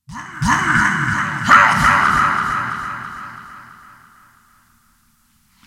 PixelPerfectionCE/assets/minecraft/sounds/mob/wither/idle4.ogg at mc116